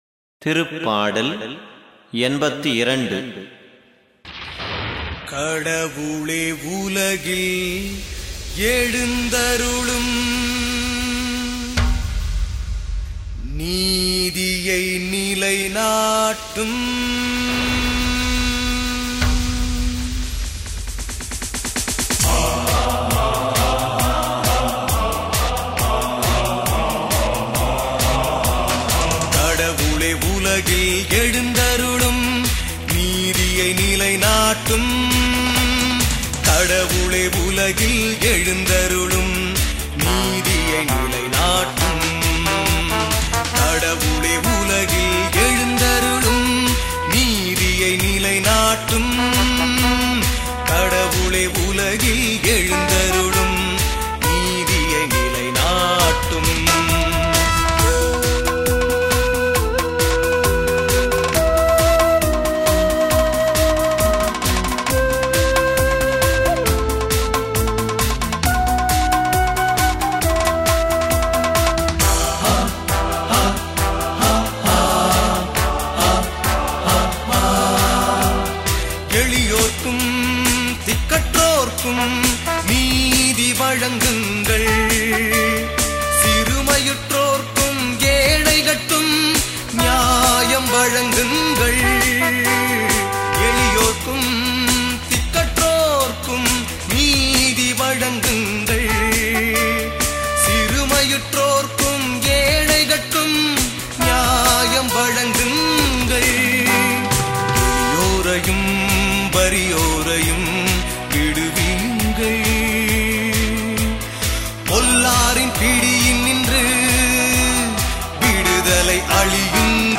பதிலுரைப் பாடல் -